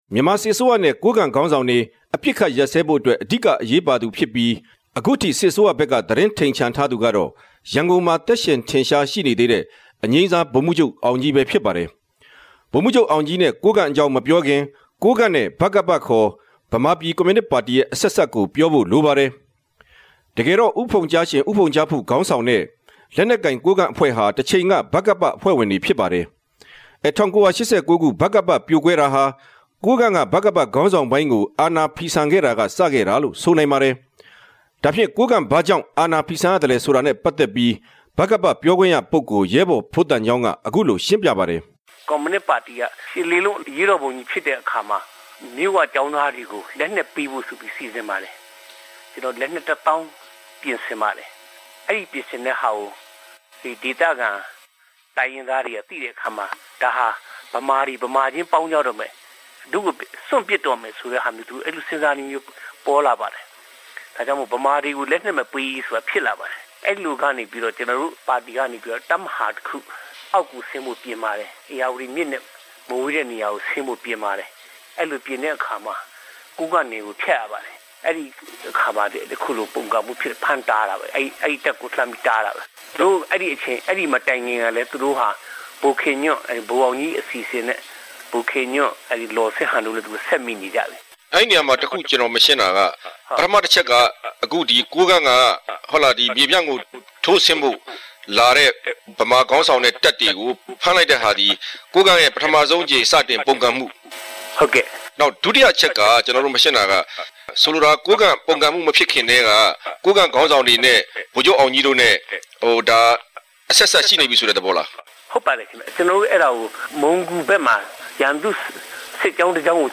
သုံးသပ်တင်ူပခဵက်။